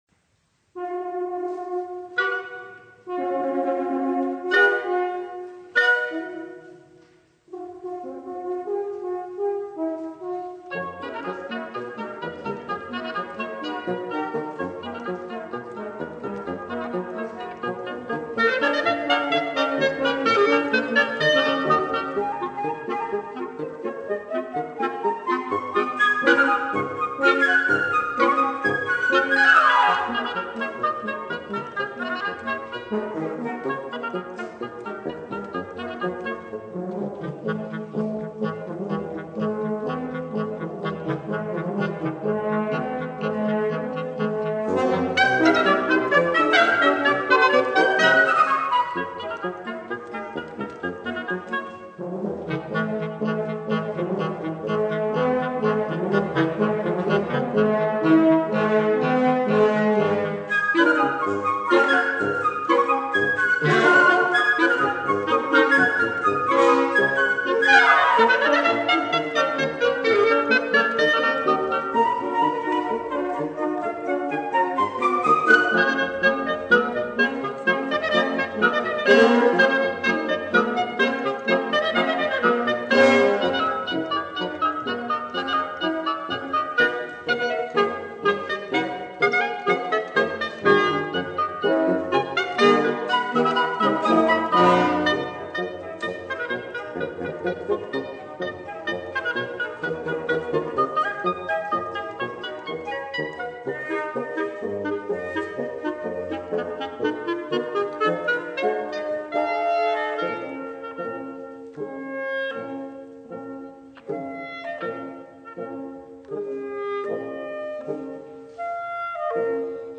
flauto
oboe
clarinetto
fagotto
corno
Musica Classica / Cameristica